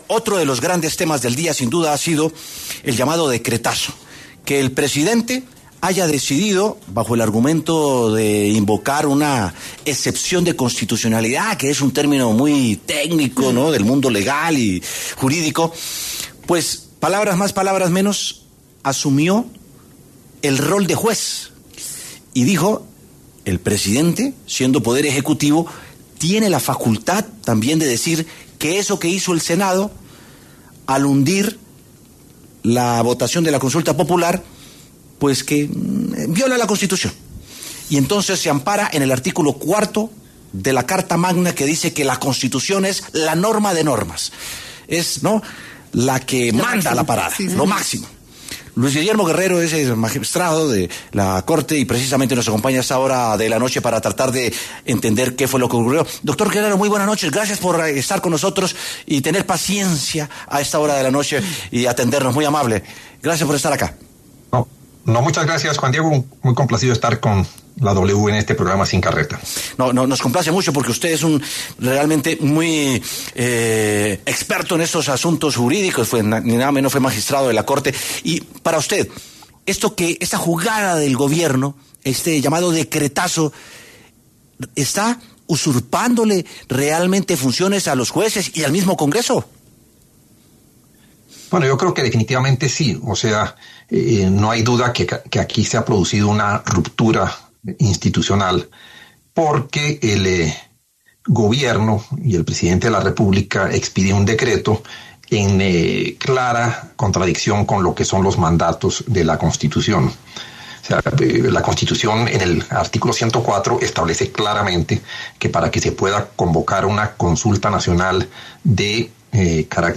Para explicar el tema, pasó por los micrófonos de W Sin Carreta el exmagistrado de la Corte Suprema, Luis Guillermo Guerrero.